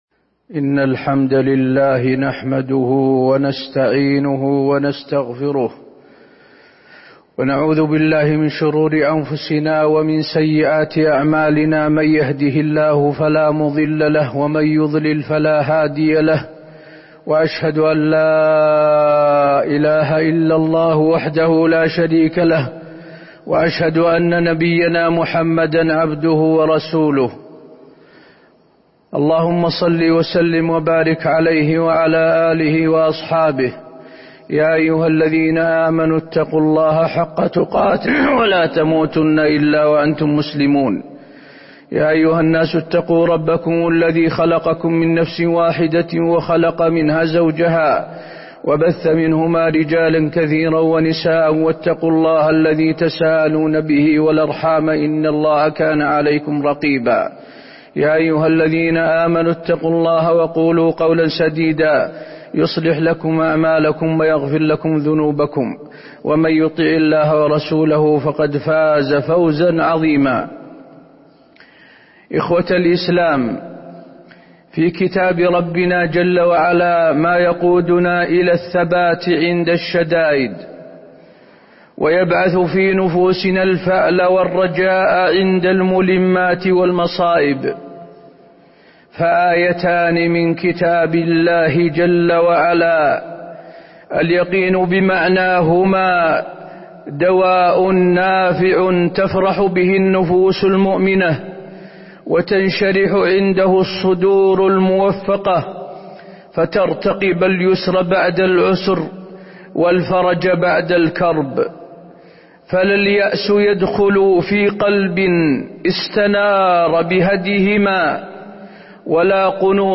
تاريخ النشر ٧ ربيع الثاني ١٤٤٣ هـ المكان: المسجد النبوي الشيخ: فضيلة الشيخ د. حسين بن عبدالعزيز آل الشيخ فضيلة الشيخ د. حسين بن عبدالعزيز آل الشيخ اليسر بعد العسر